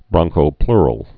(brŏngkō-plrəl)